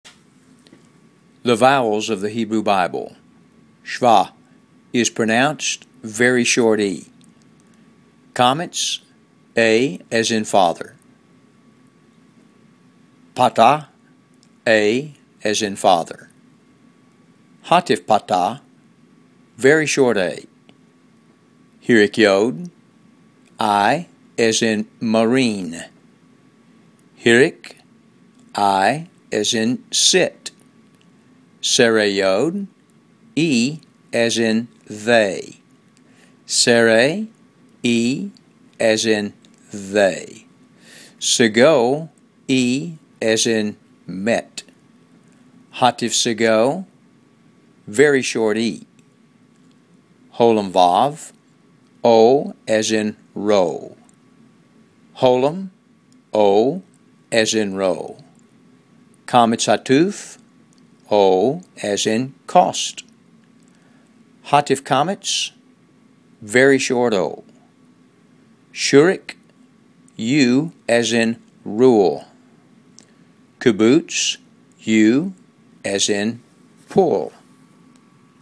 Hebrew-Vowels.m4a